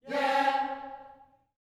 YEAH C#4L.wav